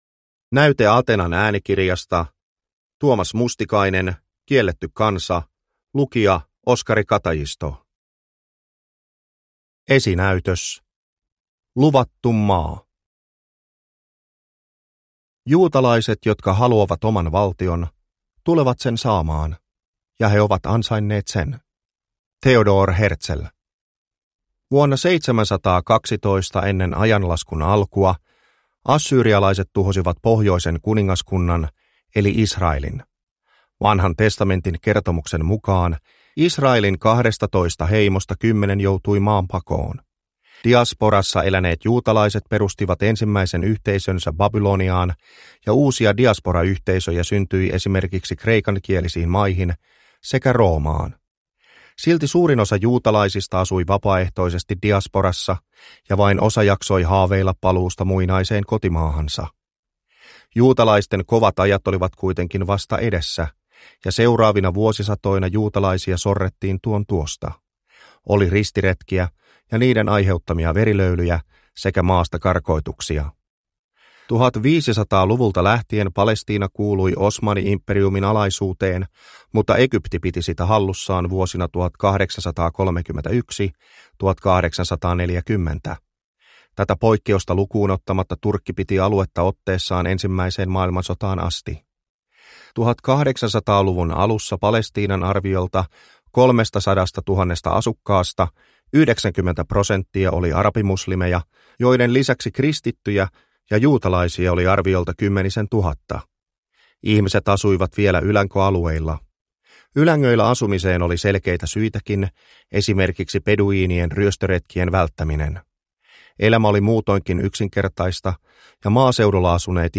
Kielletty kansa – Ljudbok – Laddas ner